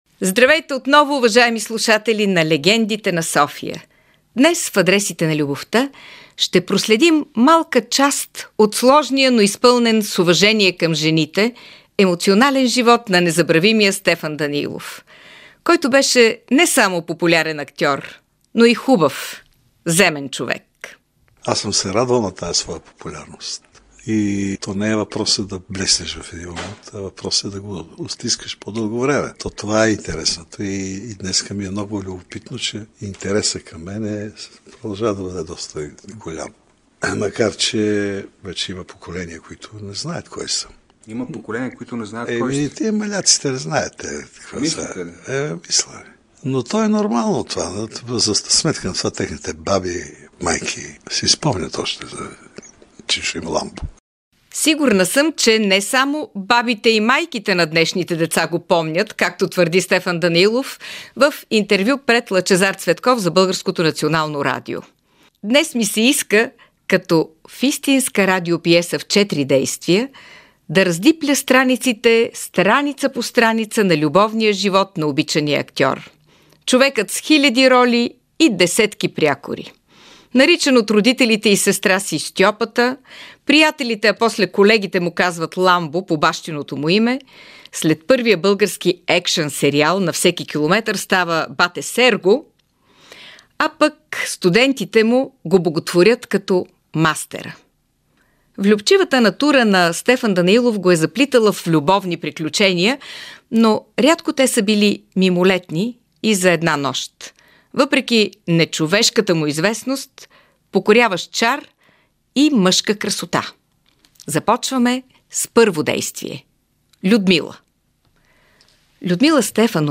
Радиоразказ в четири действия за Стефан Данаилов - Ламбо и жените в неговия живот – в Легендите на София и рубриката Адресите на любовта.